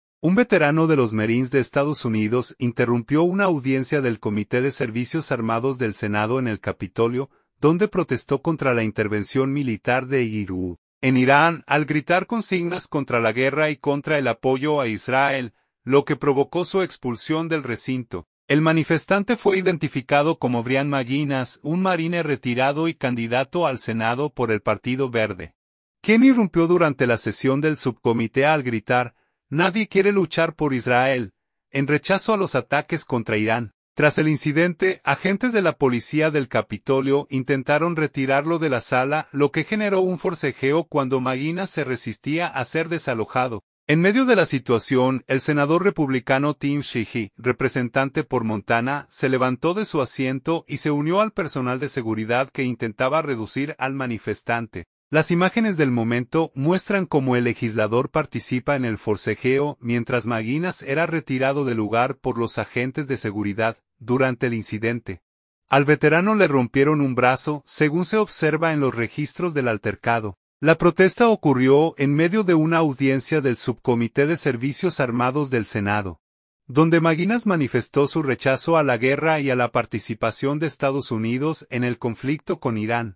Un veterano de los marines de Estados Unidos interrumpió una audiencia del Comité de Servicios Armados del Senado en el Capitolio, donde protestó contra la intervención militar de EE. UU. en Irán, al gritar consignas contra la guerra y contra el apoyo a Israel, lo que provocó su expulsión del recinto.